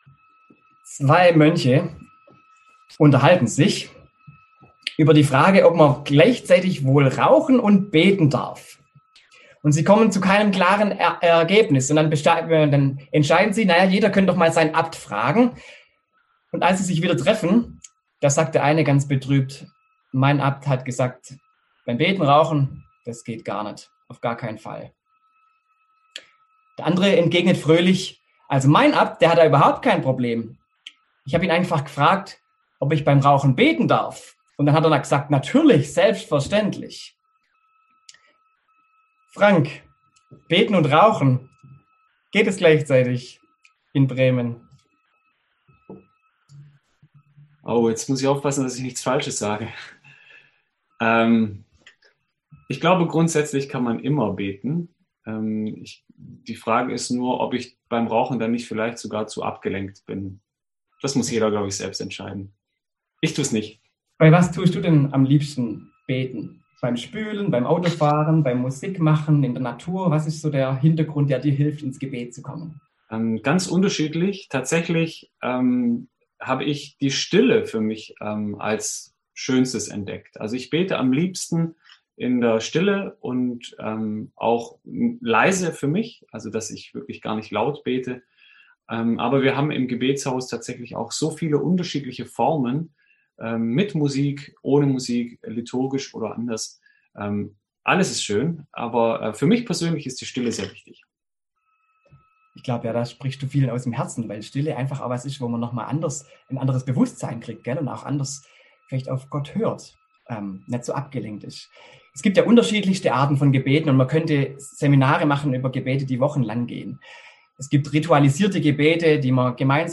Predigt
im Onlinegottesdienst am Sonntag Rogate